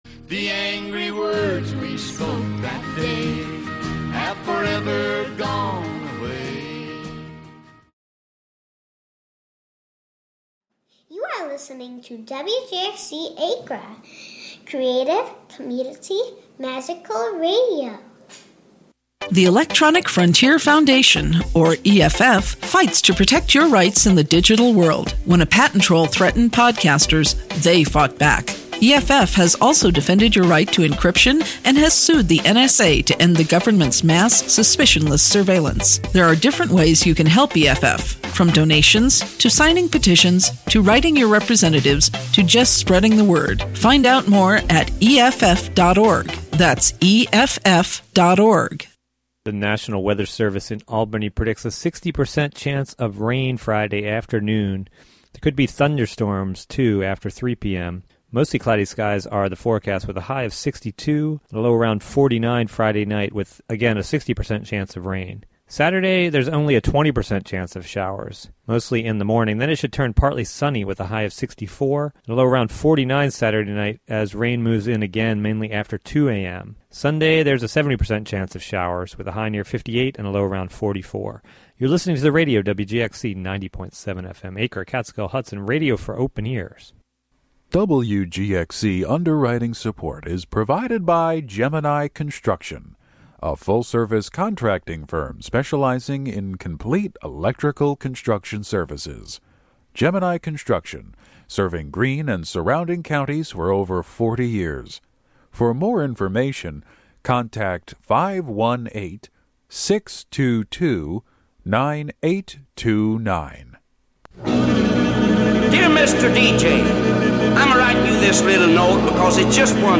New or artifact, urban or rural – City Folk is a curated field guide for the humble, a study in dirt and bone. Broadcast live from the Hudson studio.